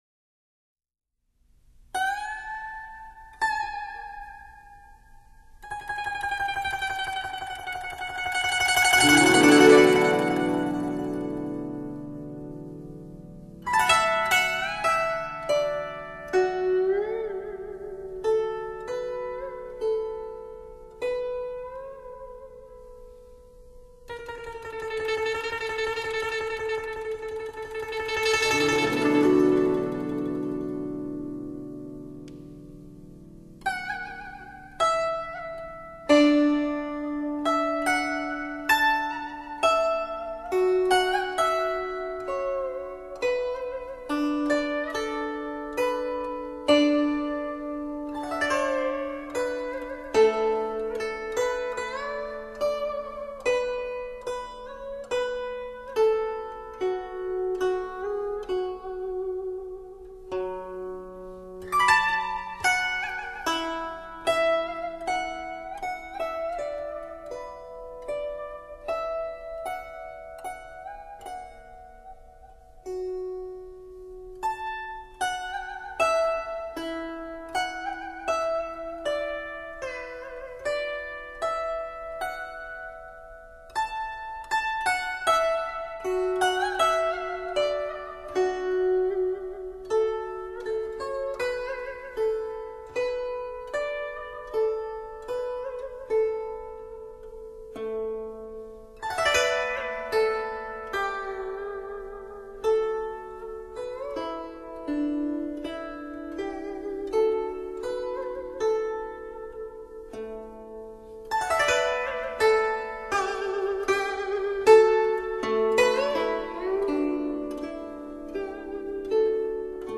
大曲有大气，小品亦见细腻。
CD的质感饱满扎实，整座古筝浮现眼前。